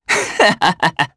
Ezekiel-Vox_Happy2_jp.wav